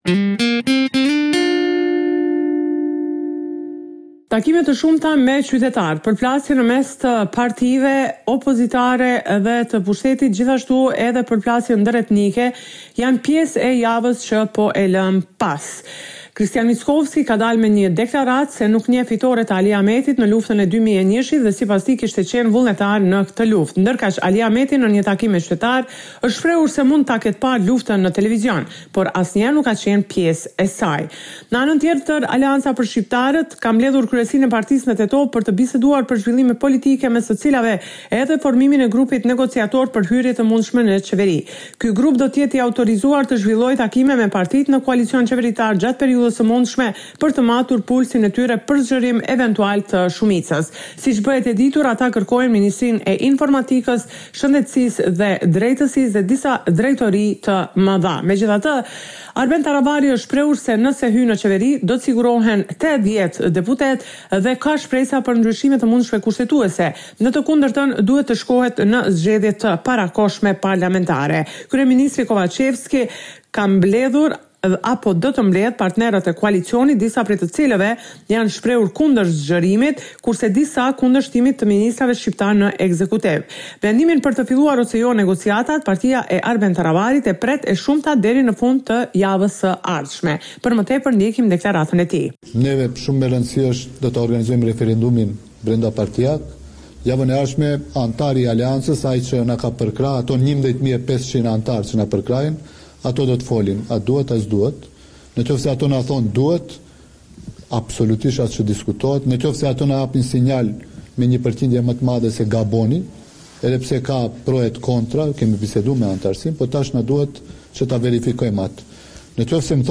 Raporti me të rejat më të fundit nga Maqedonia e Veriut.